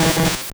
Cri d'Élekid dans Pokémon Or et Argent.